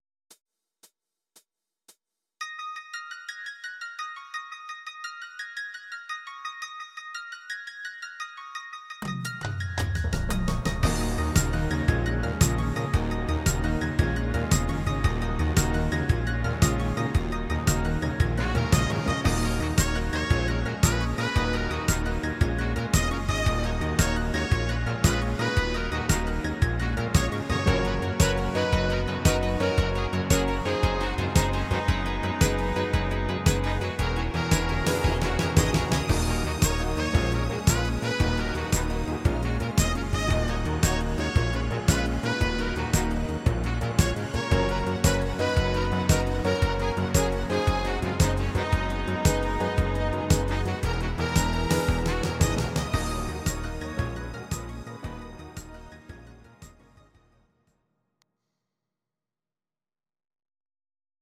These are MP3 versions of our MIDI file catalogue.
Please note: no vocals and no karaoke included.
Film theme